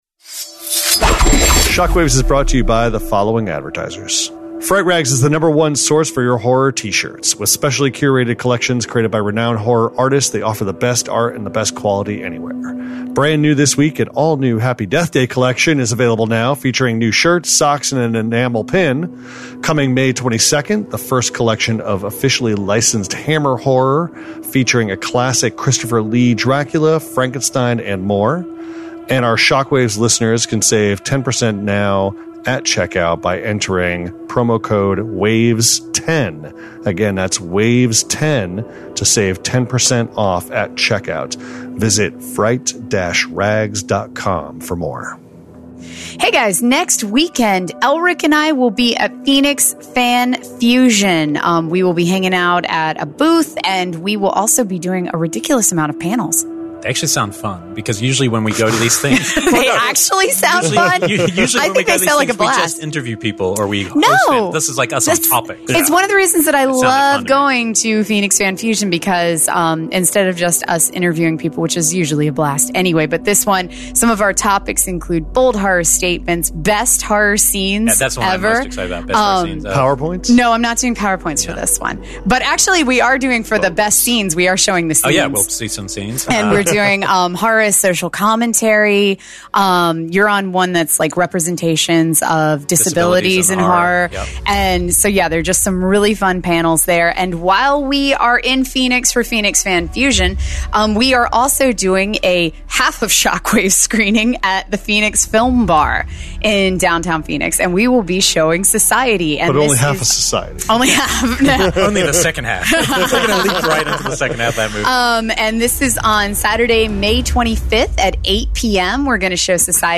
Then we're joined by rocker Dee Snider